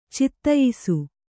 ♪ cittayisu